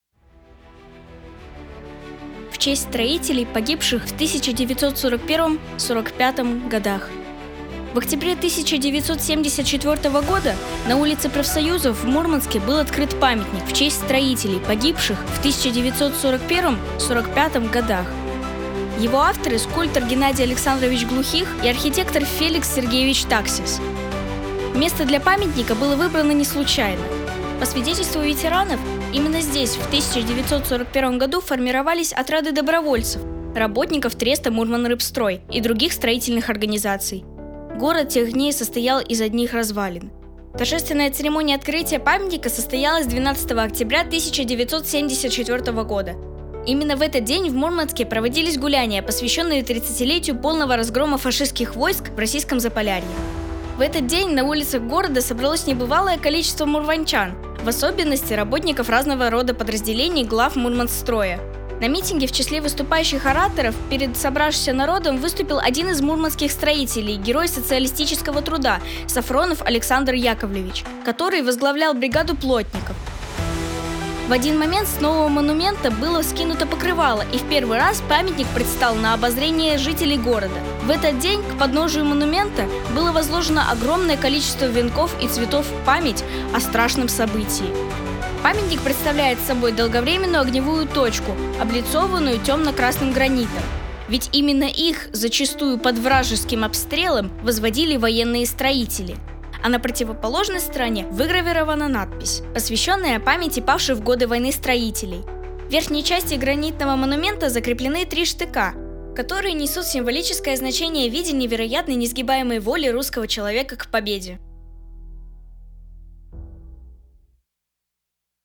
Новая аудиоэкскурсия проекта «51 история города М»
Волонтёры Центра добровольческих инициатив «Наше дело» подготовили для вас новый рассказ